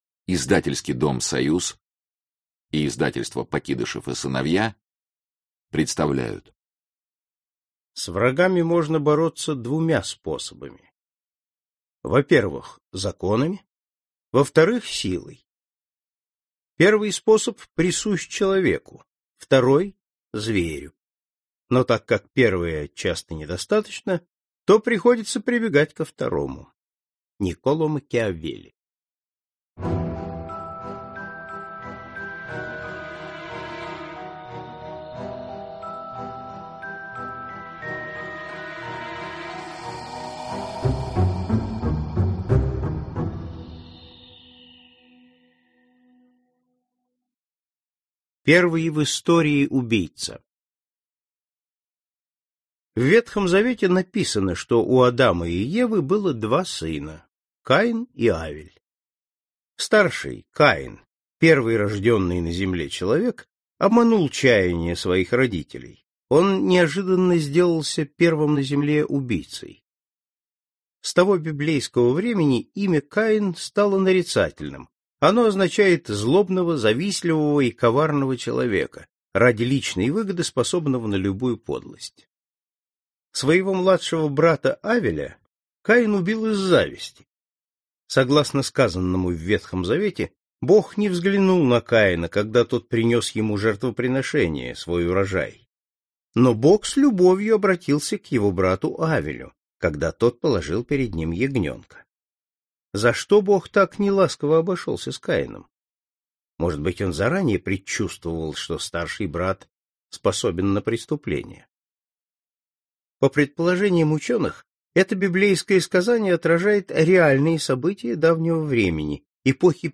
Аудиокнига Великие криминальные истории | Библиотека аудиокниг